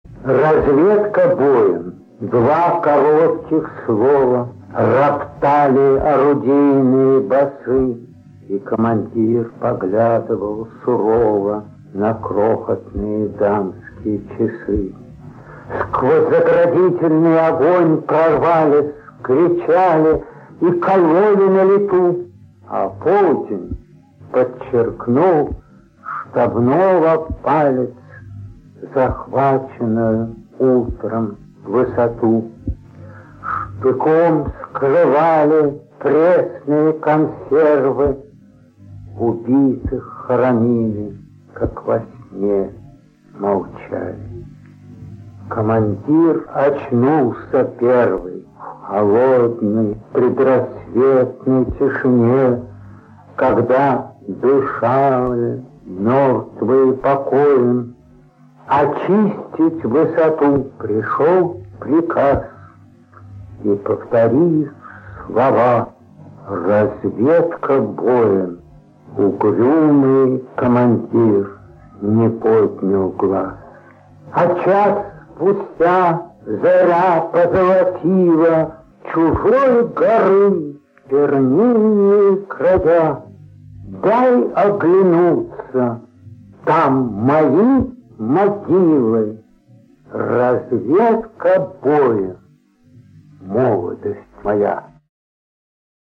3. «Илья Эренбург – Разведка боем (читает автор)» /
ilya-erenburg-razvedka-boem-chitaet-avtor